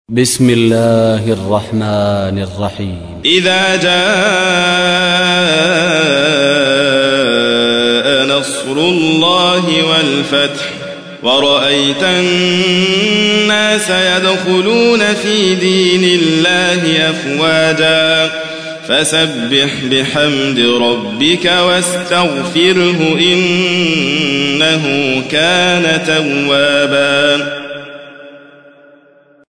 تحميل : 110. سورة النصر / القارئ حاتم فريد الواعر / القرآن الكريم / موقع يا حسين